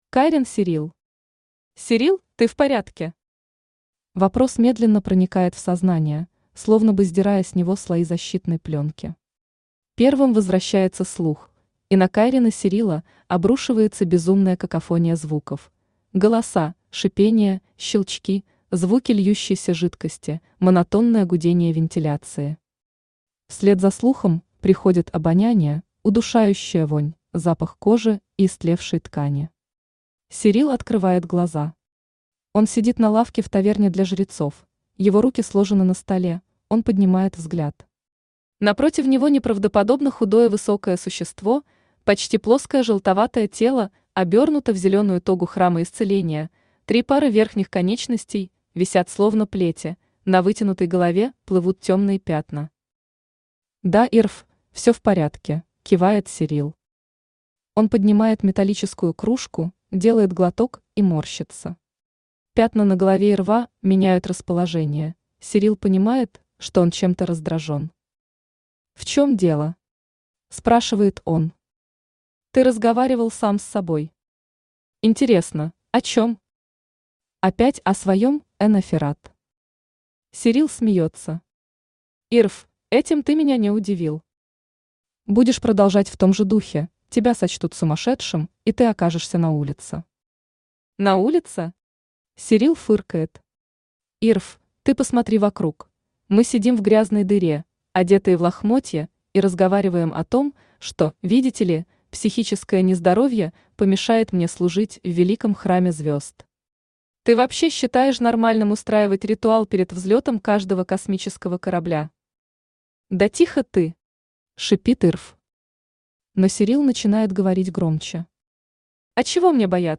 Аудиокнига Империя | Библиотека аудиокниг
Aудиокнига Империя Автор Рина Когтева Читает аудиокнигу Авточтец ЛитРес.